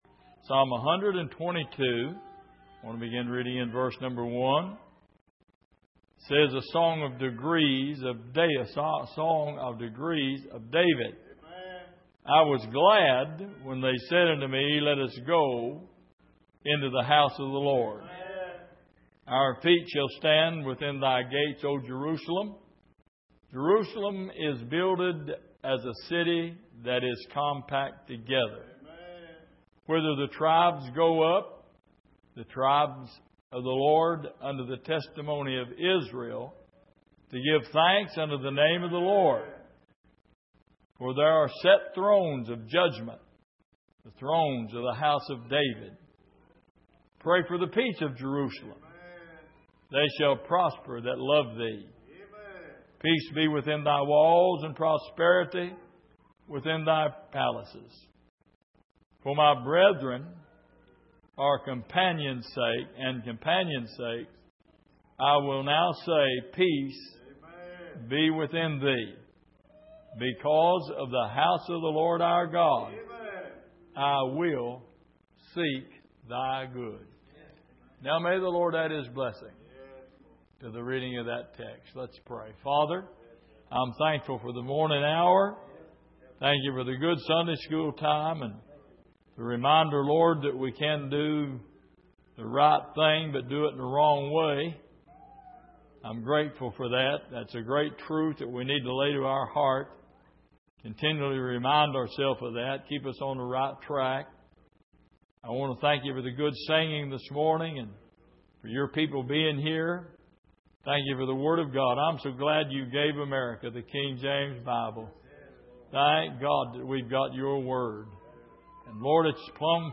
Exposition of the Psalms Passage: Psalm 122:1-9 Service: Sunday Morning Why Are You Here?